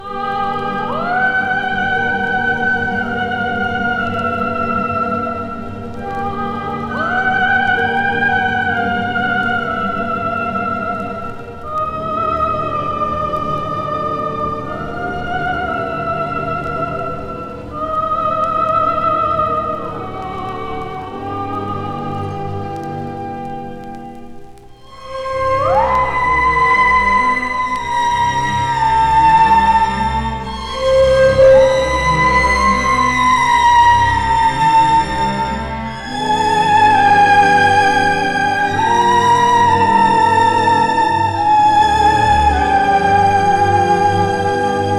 オーケストラによる美しいメロディと効果的に現れる歌声、暗さも魅力的です。
Stage & Screen, Soundtrack　USA　12inchレコード　33rpm　Mono